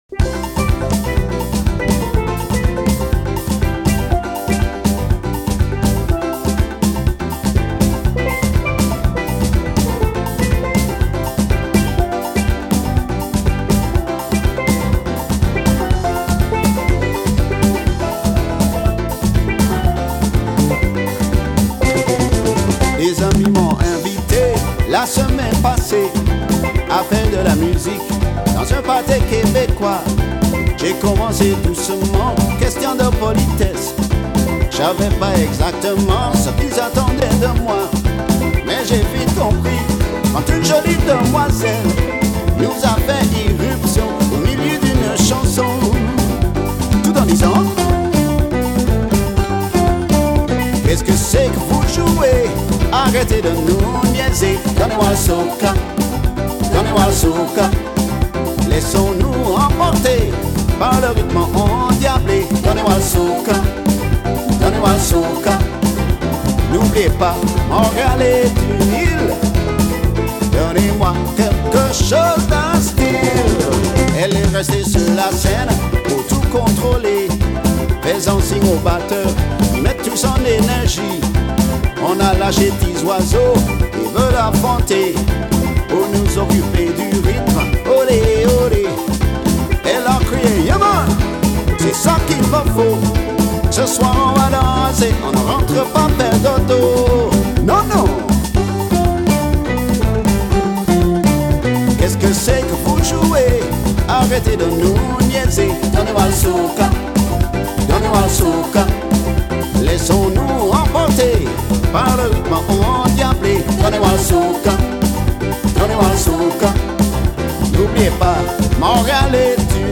Soca, Calypso, Reggae, Compa, Zouk and African music.